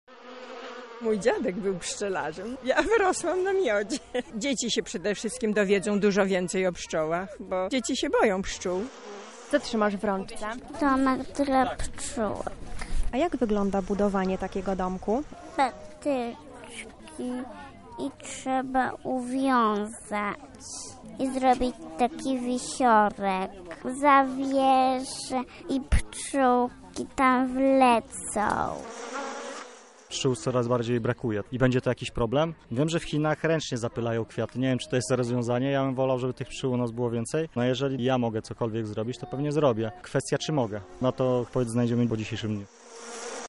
Każdy kto przyszedł, poznał tajniki życia tych owadów, ich rolę w ekosystemie i sposoby ochrony. Na miejscu była nasza reporterka.